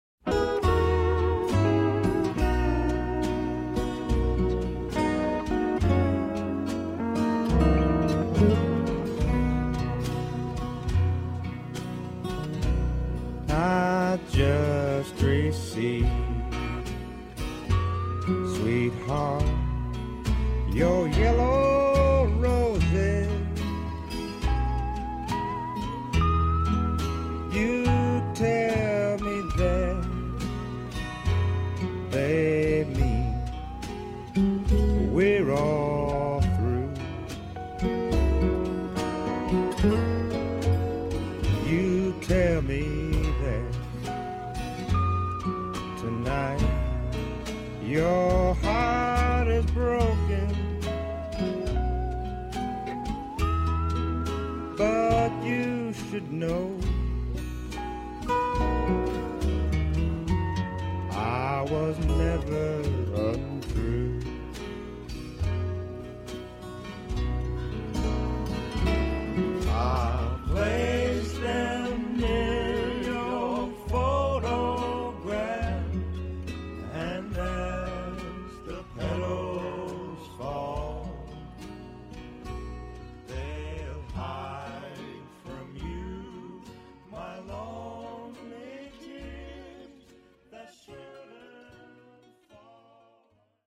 legendary Hawaiian musicians